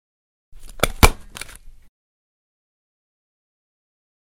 Звуки степлера
Звук скрепления бумаг степлером металлическими скобами